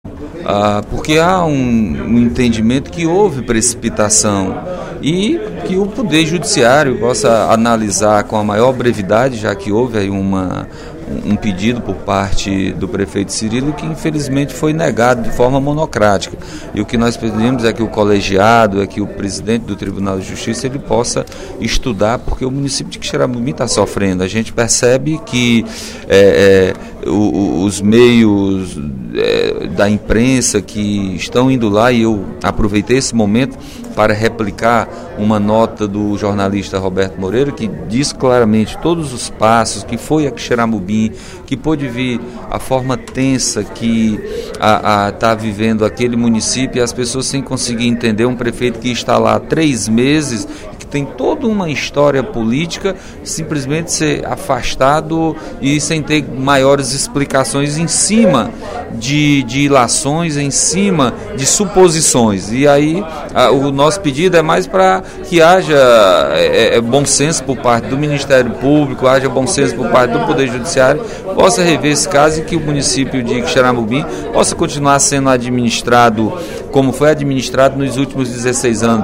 O afastamento do prefeito do município de Quixeramobim, Cirilo Pimenta, voltou a ser abordado pelo deputado Nenen Coelho (PSD). Em pronunciamento na manhã desta quinta-feira (18/04), o parlamentar fez um apelo ao Ministério Público para que se analise a situação com maior profundidade.